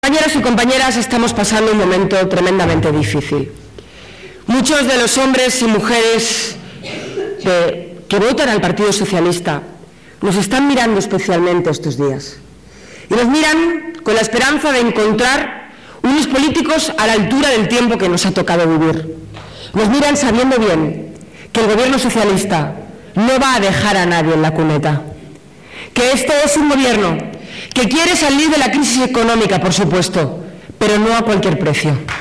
Leire Pajín, durante el acto en San Clemente (Cuenca)
Pajín, que participó en un acto ante más de 400 militantes y simpatizantes socialistas en la localidad conquense de San Clemente, insistió en que “mientras los socialistas hacemos un esfuerzo por explicar nuestra política con argumentos, lo único que encontramos en el Partido Popular son líos internos, desconfianza los unos en los otros e insultos y palabras altisonantes contra las políticas del Gobierno”.